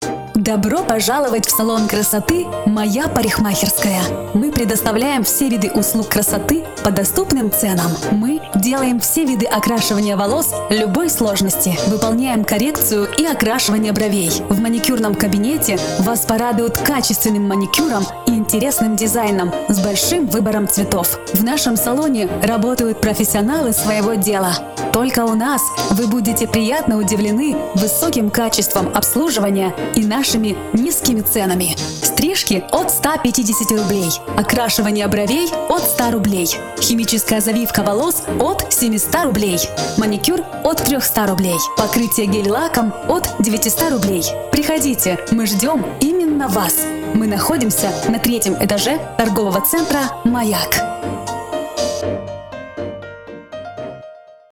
Озвучка Вашего текста, с возможностью создания индивидуальной музыкальной подложки.